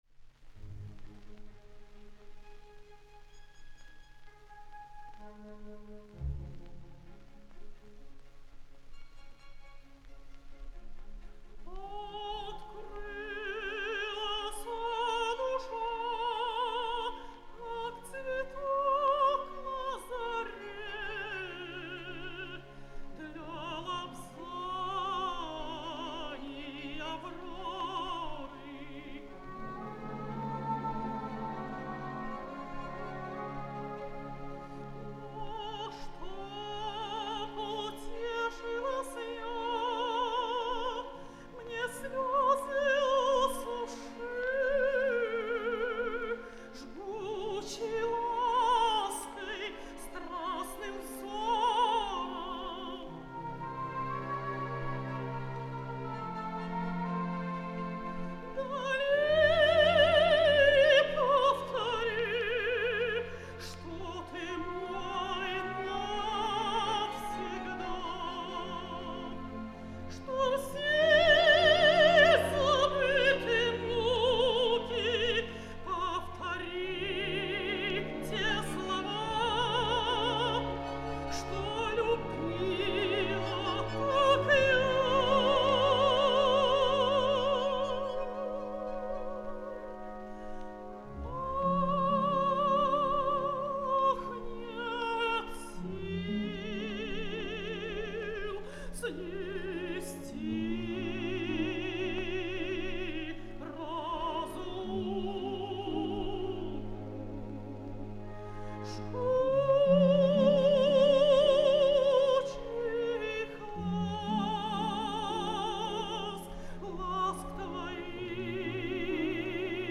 Вероника Борисенко, Орк. ГАБТ СССР, дир. Б.Хайкин - Ария Далилы (К.Сен-Санс. Самсон и Далила, 2 д.) (1955)
veronika-borisenko,-ork.-gabt-sssr,-dir.-b.haykin---ariya-dalilyi-(k.sen-sans.-samson-i-dalila,-2-d.)-(1955).mp3